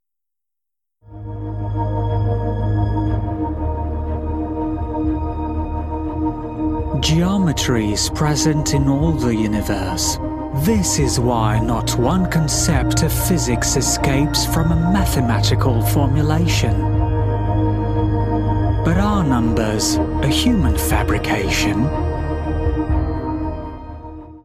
French (Canadian)
French (Parisienne)
Yng Adult (18-29) | Adult (30-50)
Movie Trailer Voice Overs